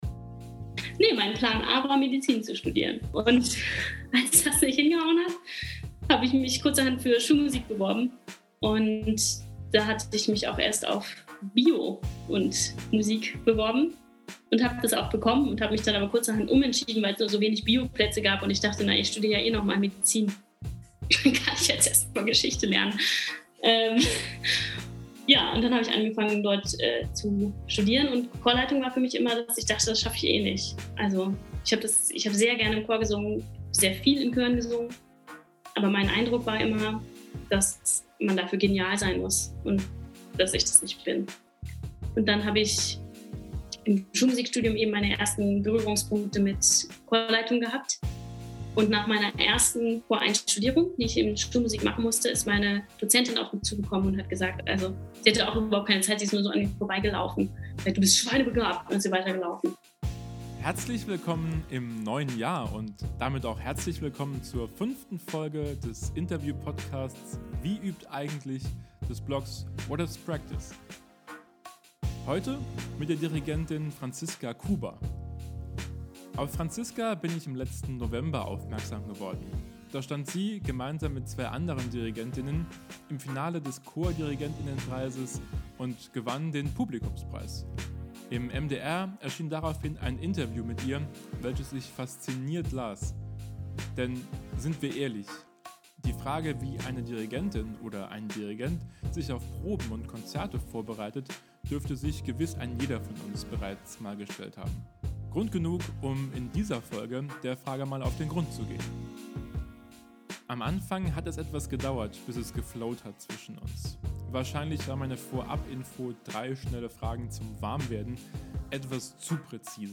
Und dann, als am Ende des Interviews alle Fragen gestellt waren, haben wir noch ein bisschen aus dem Nähkästchen geplaudert. Quasi Off-the-Record.